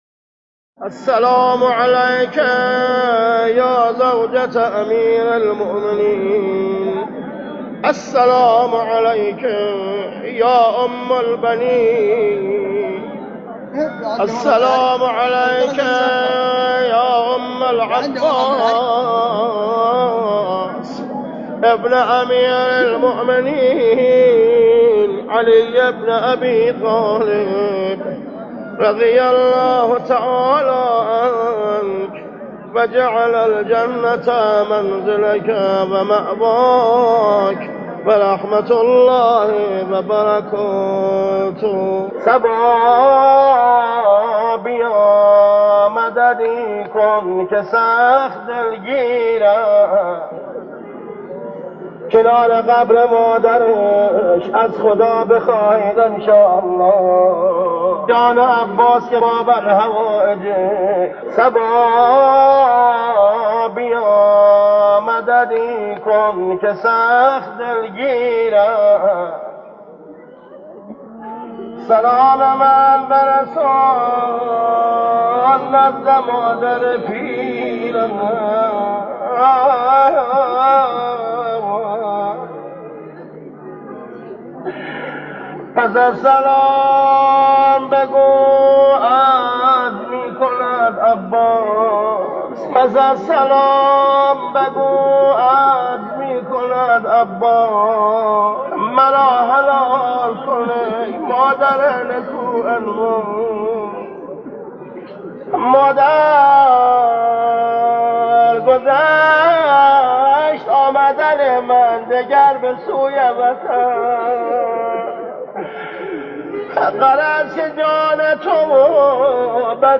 صدای ماندگار مداحان دیروز/ ۱۱